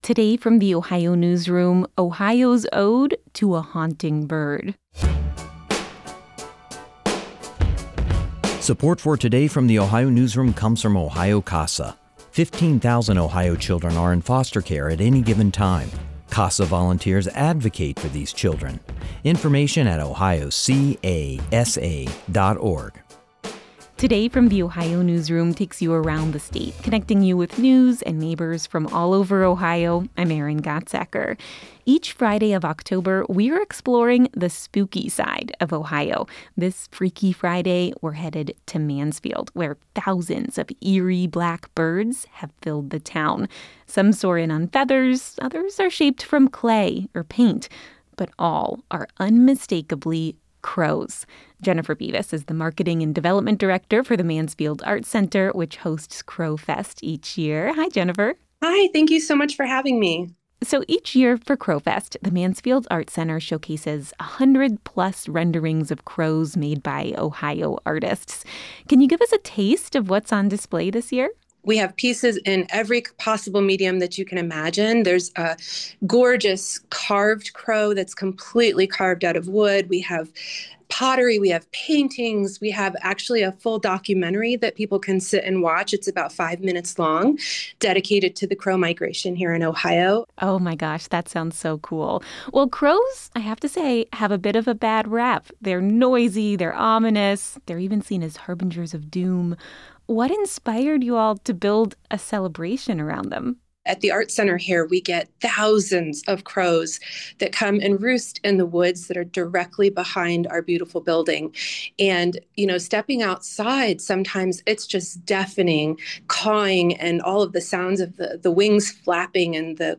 This conversation has been lightly edited for clarity and brevity.